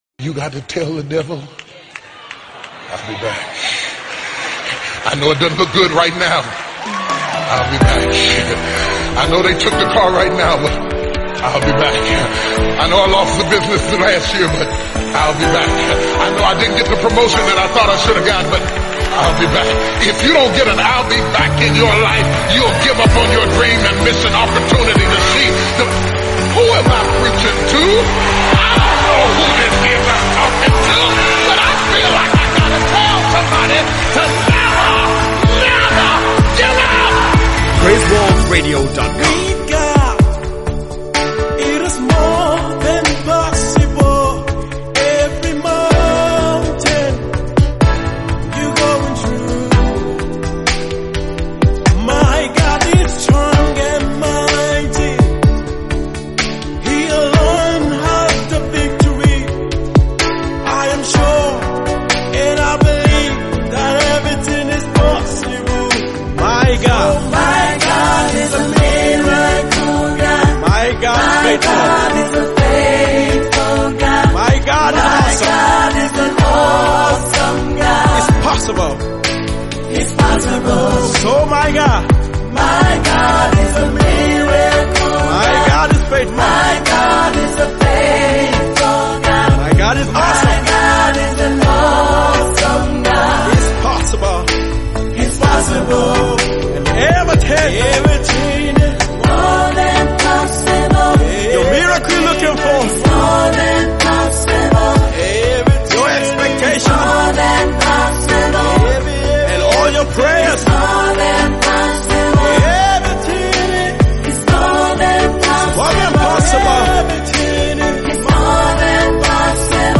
re-assuring song